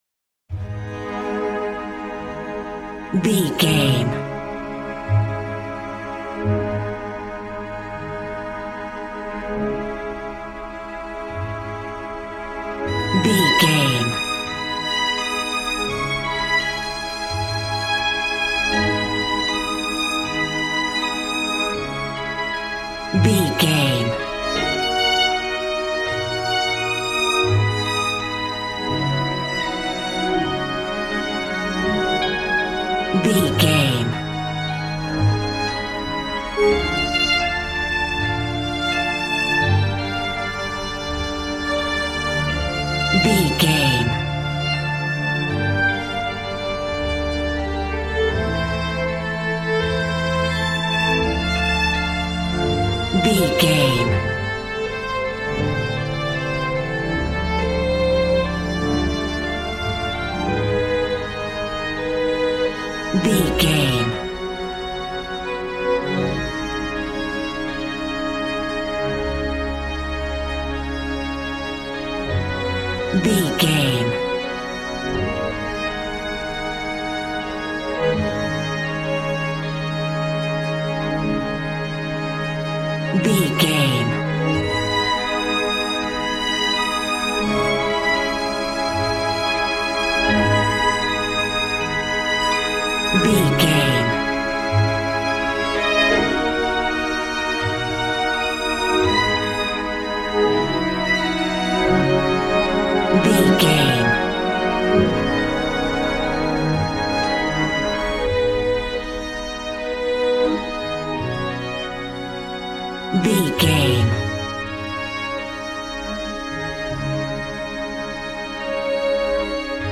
Aeolian/Minor
A♭
Fast
joyful
conga
80s